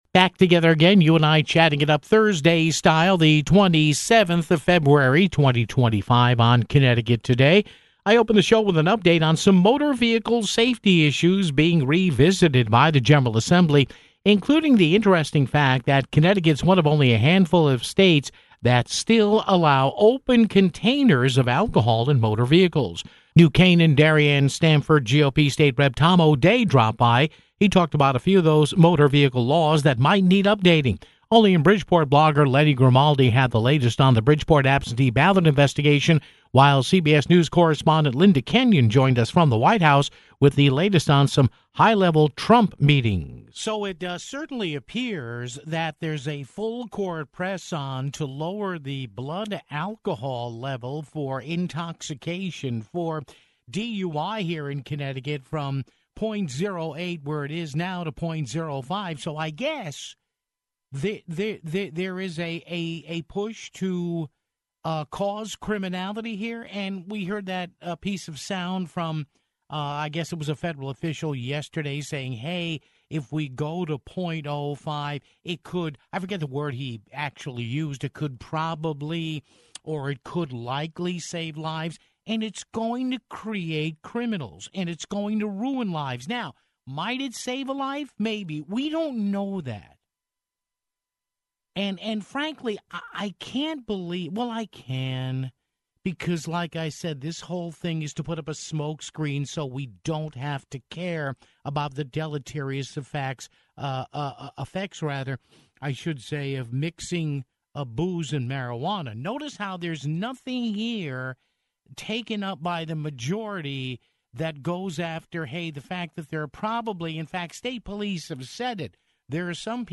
New Canaan/Darien/Stamford GOP State Rep. Tom O'Dea talked about a few of those motor vehicle laws that might need updating (16:15).